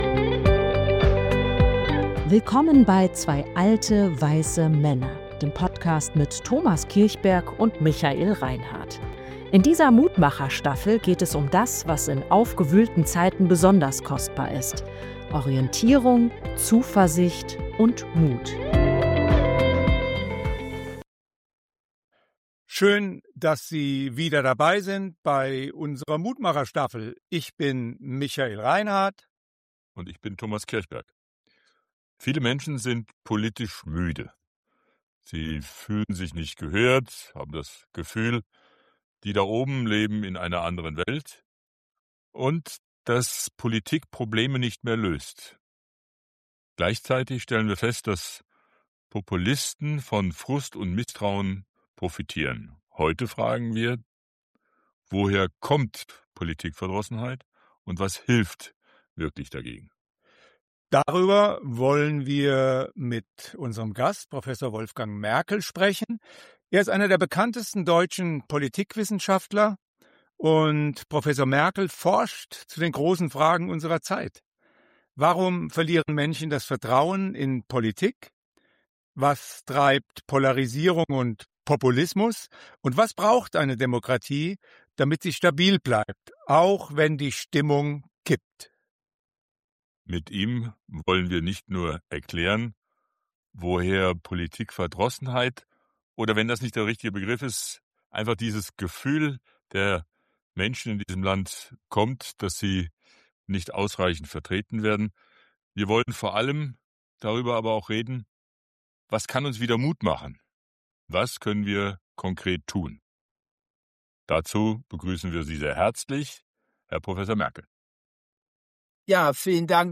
Darüber sprechen wir in dieser Folge mit dem Politikwissenschaftler Professor Wolfgang Merkel.